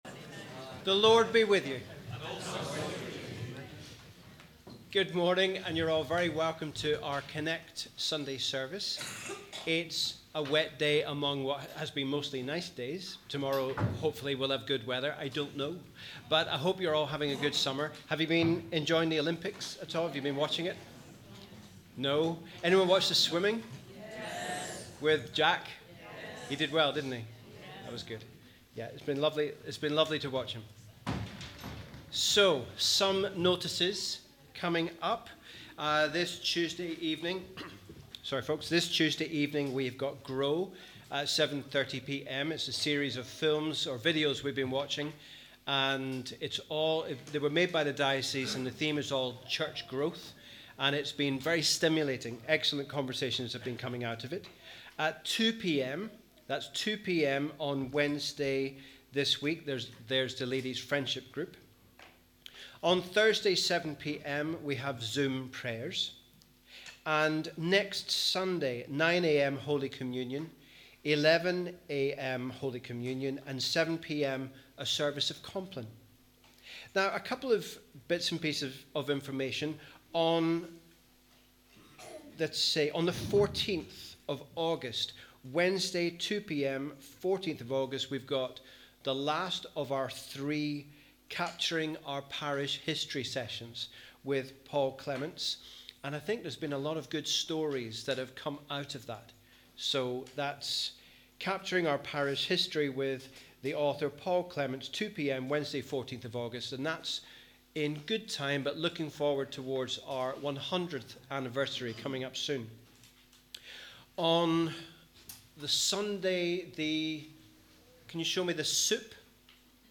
Wherever you are, we welcome you to our Connect service on the tenth Sunday after Trinity, as we continue to think about the Kingdom of God.